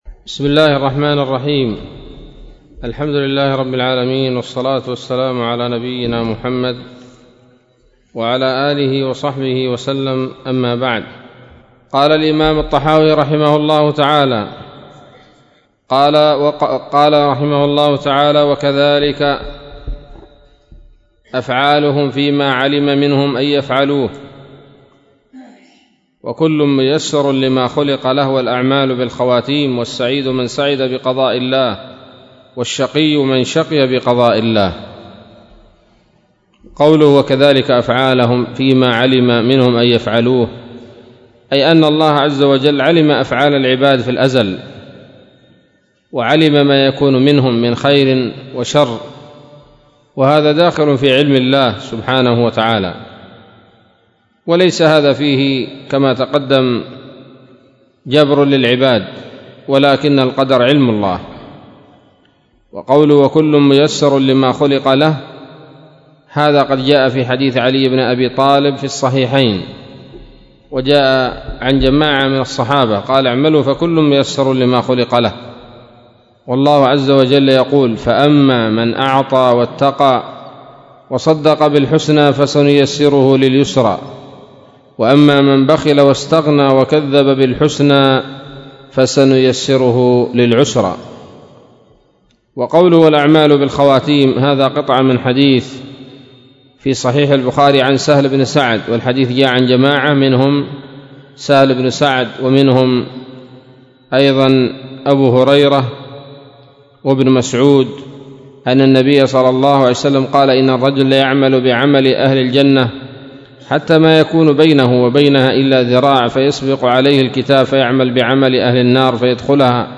الدروس العلمية العقيدة الطحاوية شروح العقيدة
الدرس العشرون من شرح العقيدة الطحاوية